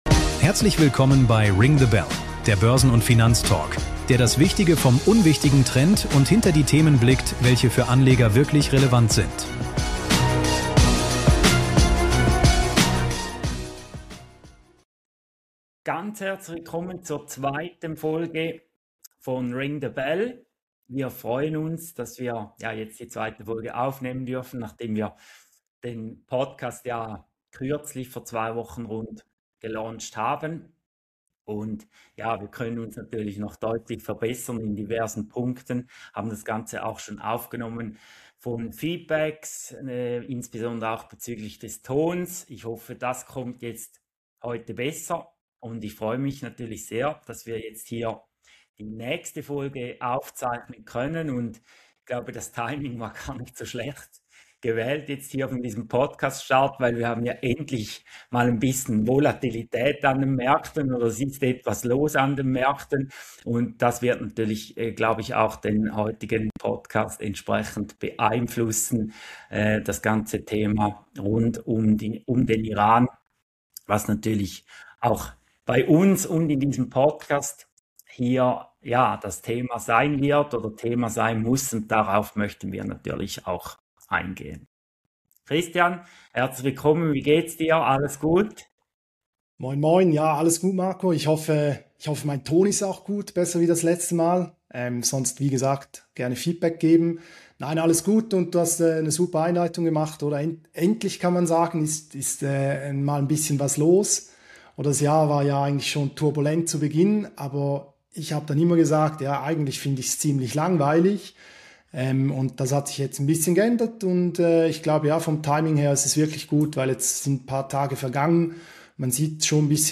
Praxisnah, bankenunabhängig und mit reichlich Skin in the Game. Das ist Ring the Bell – der Börsen- und Finanztalk, der das Wichtige vom Unwichtigen trennt.